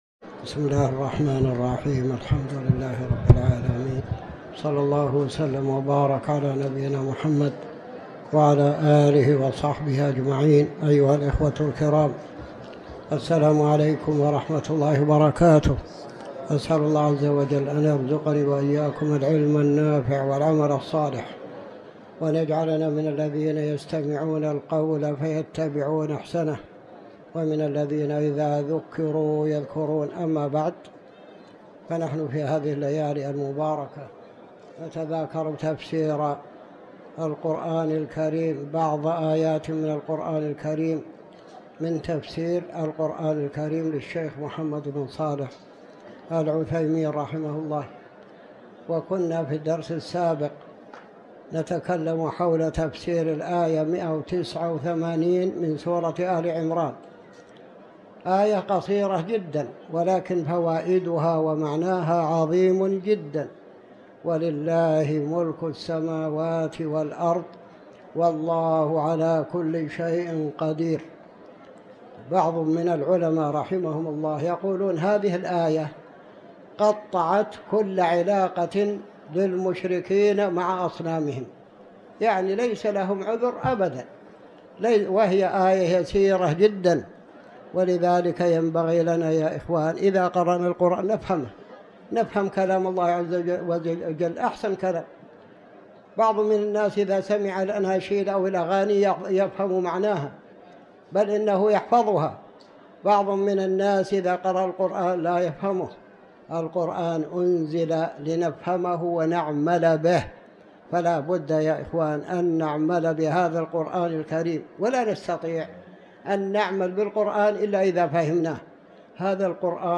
تاريخ النشر ١٩ رجب ١٤٤٠ هـ المكان: المسجد الحرام الشيخ